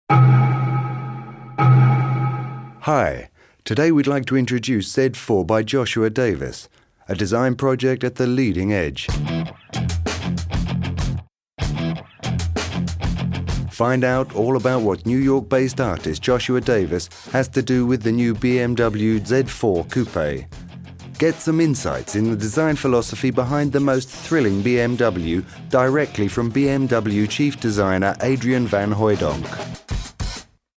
Sprecher englisch uk.
Sprechprobe: eLearning (Muttersprache):